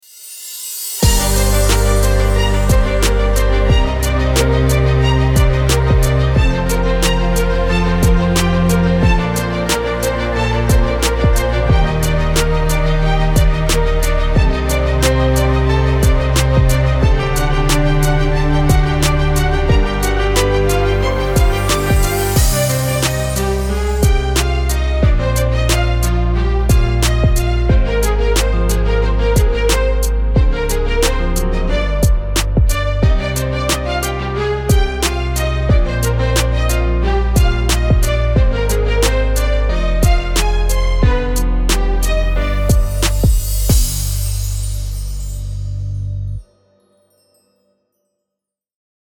rollstuhltransport-hintergrundmusik.mp3